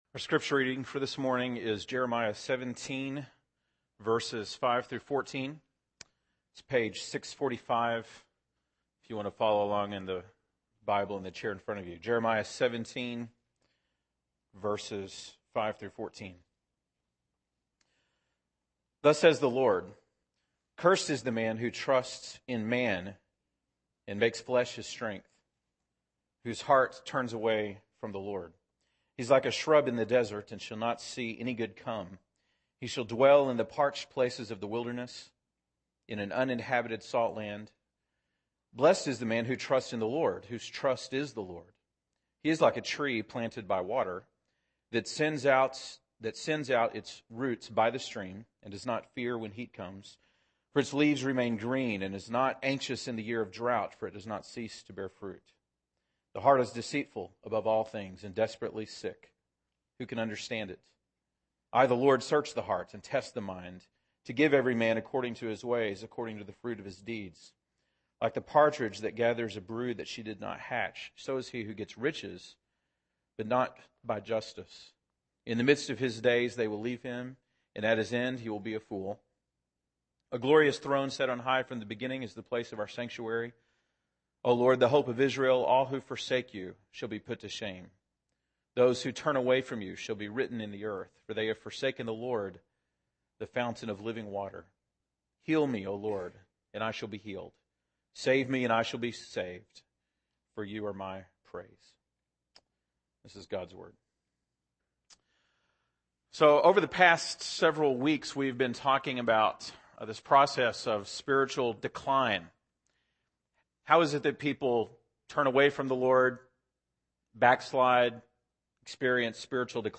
April 10, 2011 (Sunday Morning)